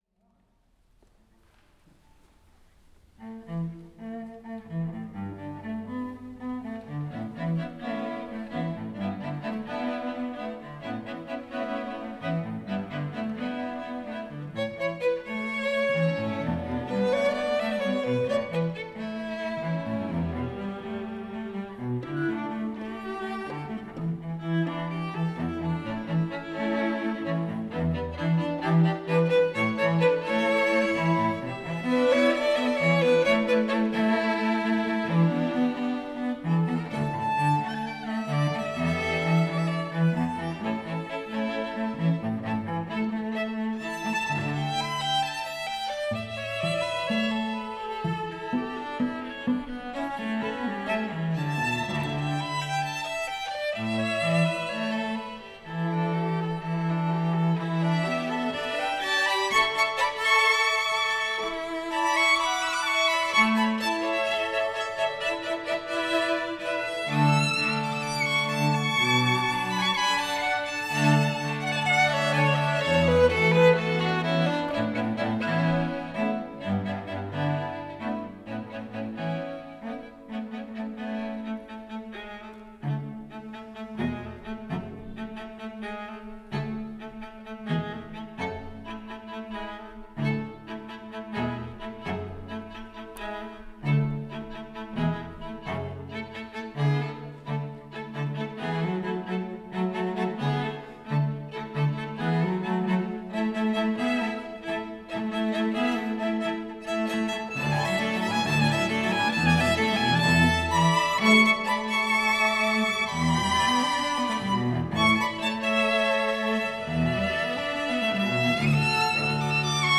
for String Quartet (2022)
The style is light and rhythmic, with energy and joy.